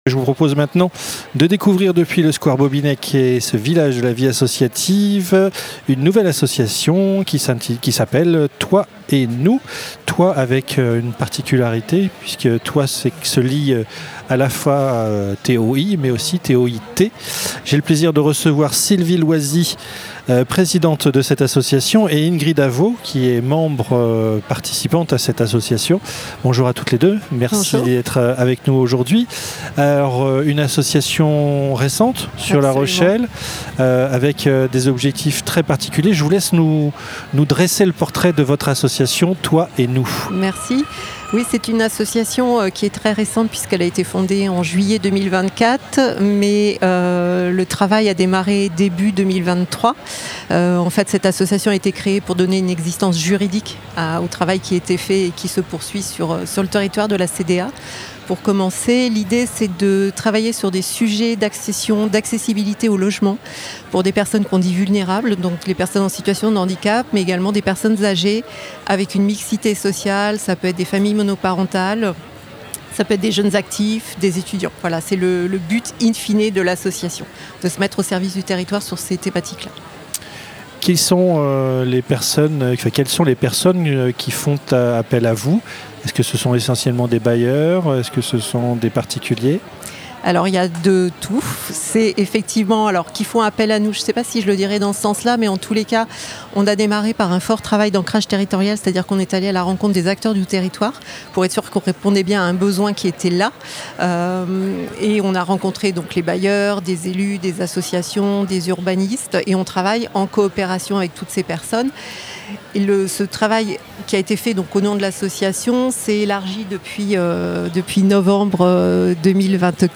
En septembre dernier, lors du Village des Associations à La Rochelle, nous avions eu l’occasion de rencontrer de nombreuses associations du territoire.
Nous replongions ce matin dans cette interview.